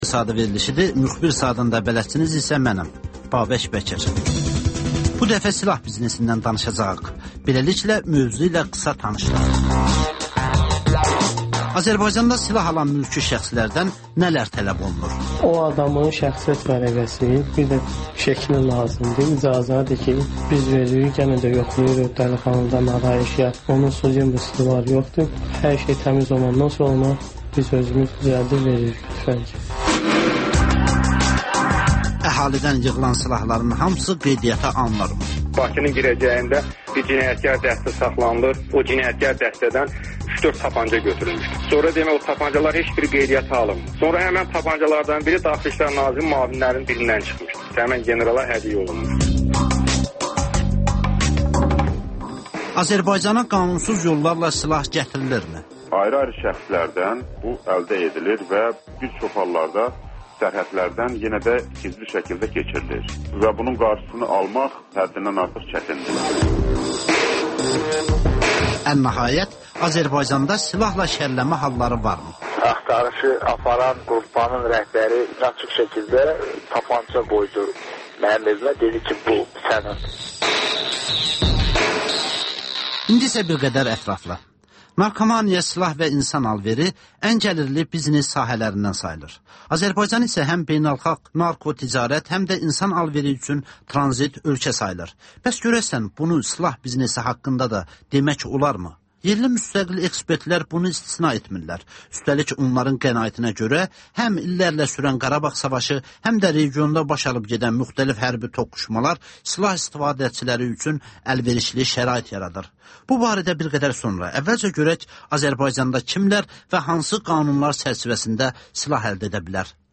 XÜSUSİ REPORTAJ